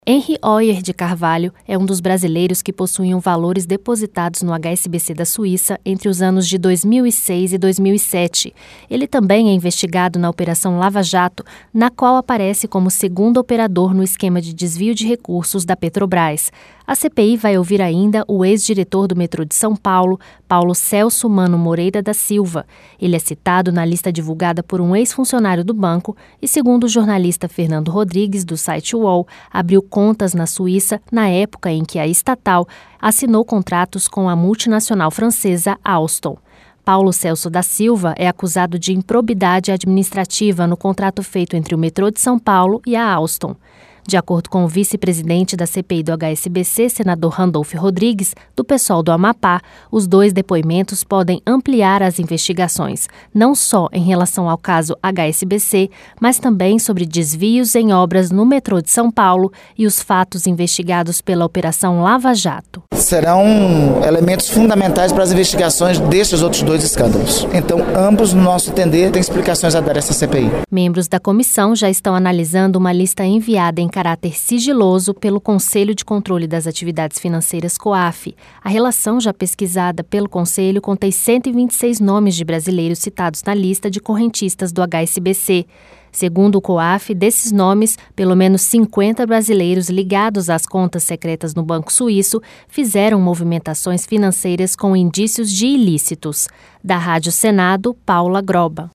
Detalhes com a repórter